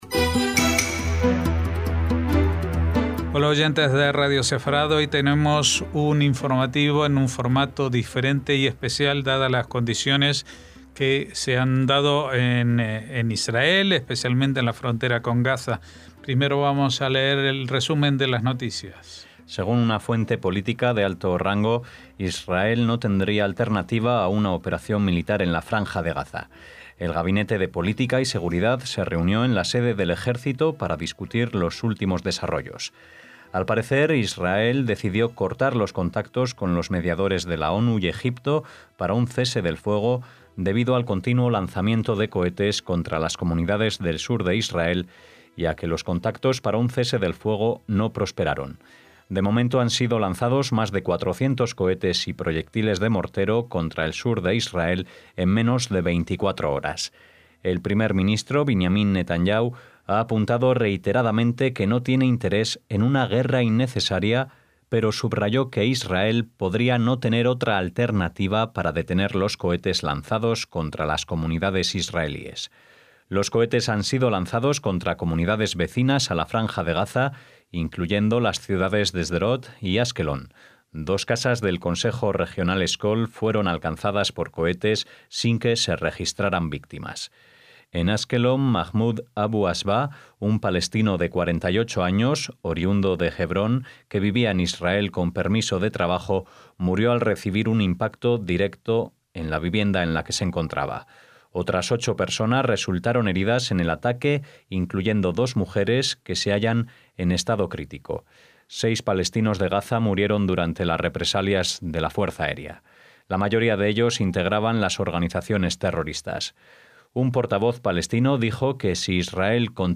Especial informativo sobre los lanzamientos de cohetes desde Gaza y la respuesta israelí 14/11/2018
NOTICIAS